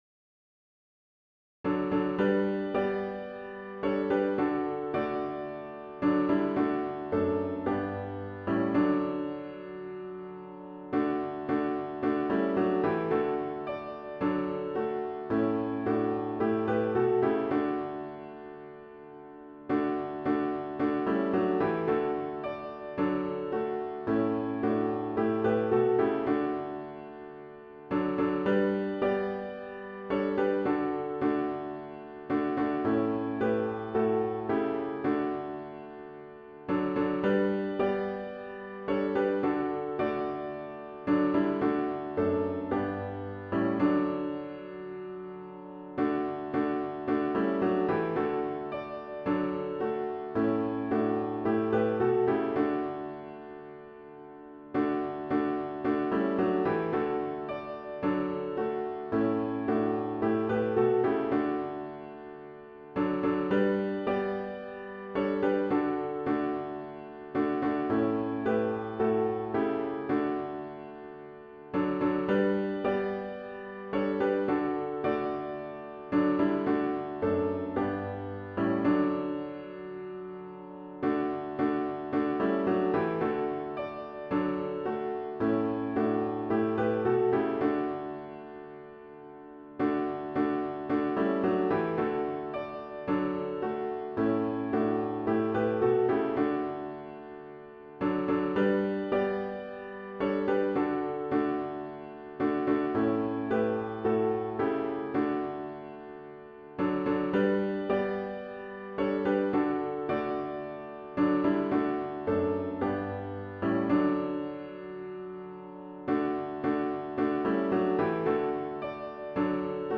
HYMN “Savior, like a Shepherd Lead Us” GtG 187    Words: Thrupp’s Hymns for the Young, 1836, alt.